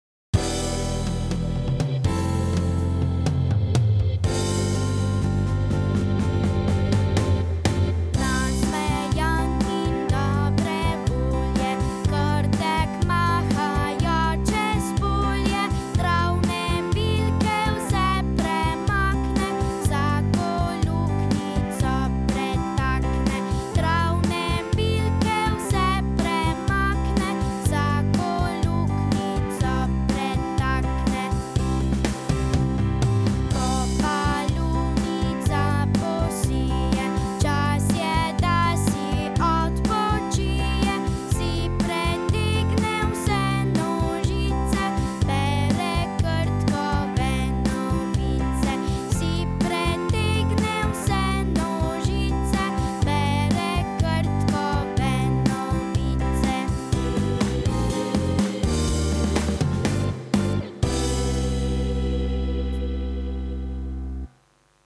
Krtek mižek - Otroška pesmica